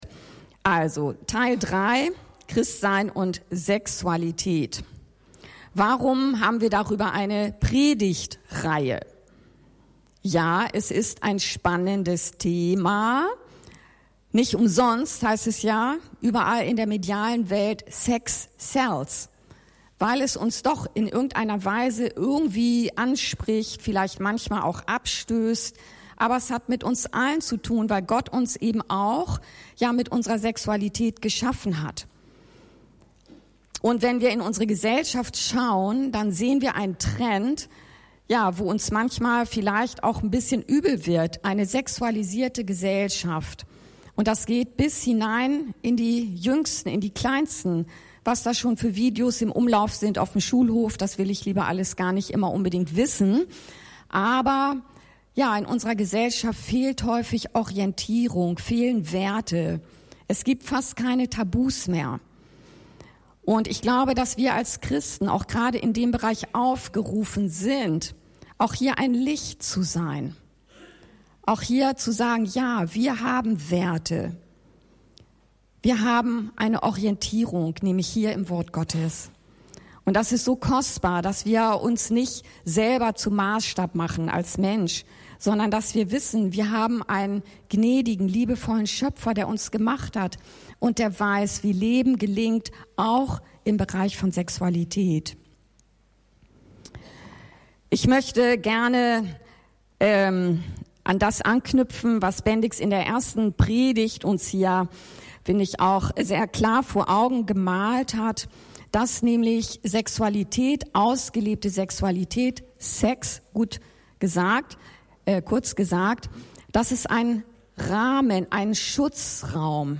Sexualität und Christsein – Teil 3 ~ Anskar-Kirche Hamburg- Predigten Podcast